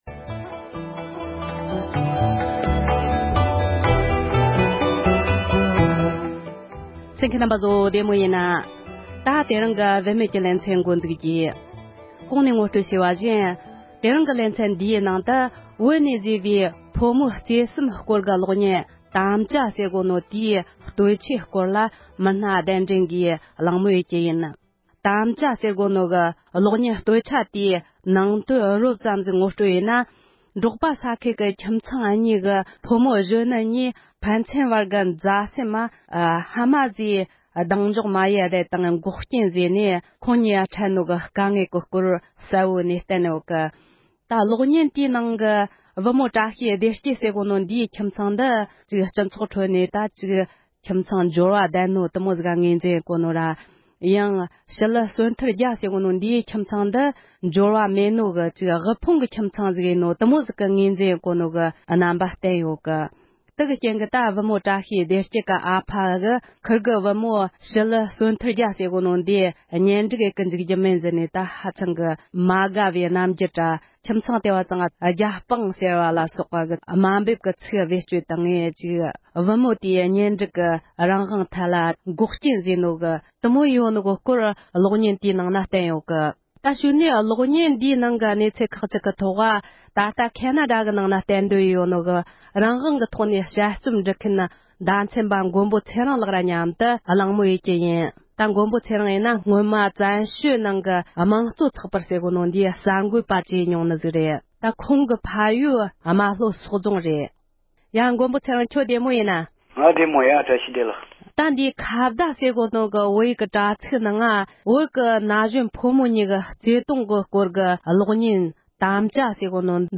དམ་བཅའ་ཞེས་པའི་བོད་པའི་གློག་བརྙན་དེ་དང་འབྲེལ་ནས་བོད་མིའི་སྤྱི་ཚོགས་ཁྲོད་ཕོ་མཆོག་དང་མོ་དམན་གྱི་སྲོལ་རྒྱུན་ཐད་བགྲོ་གླེང༌།